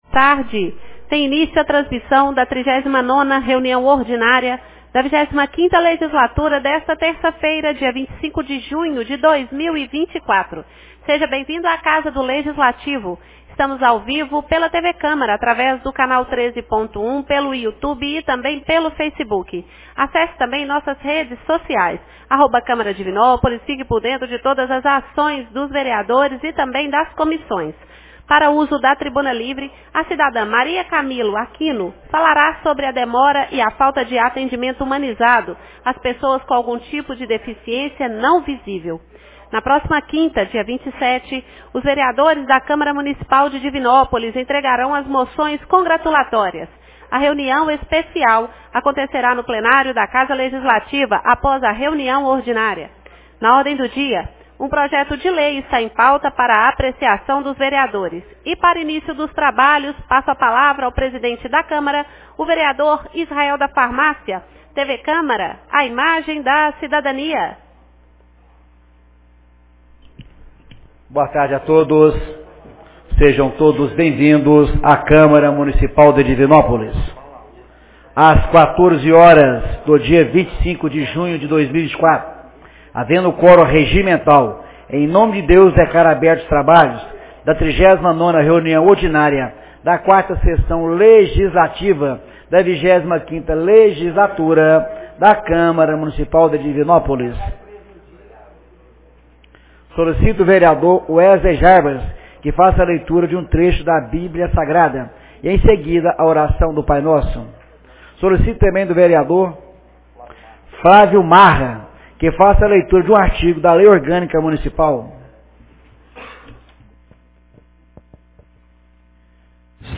39ª Reunião Ordinária 25 de junho de 2024